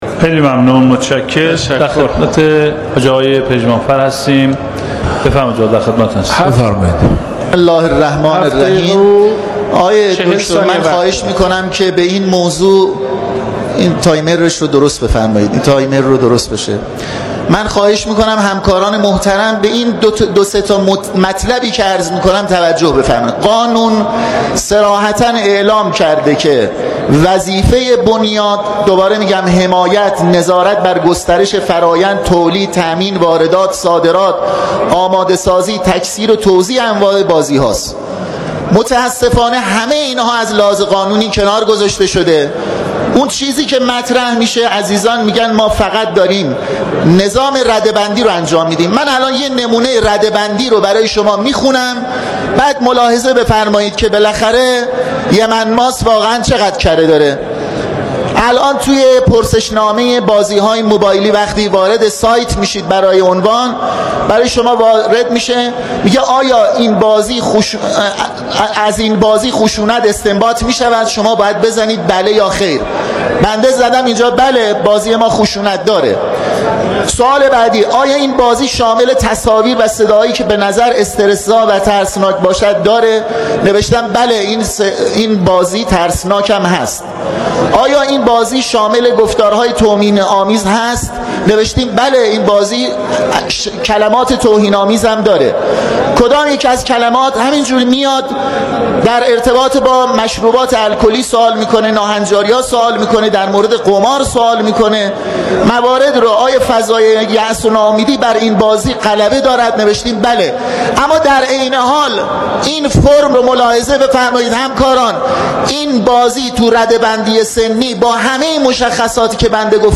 روز چهارشنبه ۱۸ مهرماه ۹۷ در مجلس شورای اسلامی موارد و ادعاهایی علیه بنیاد ملی بازی‌های رایانه‌ای توسط یکی از نمایندگان محترم در صحن علنی مجلس شورای اسلامی در قالب پرسش از وزیر فرهنگ و ارشاد اسلامی مطرح شد.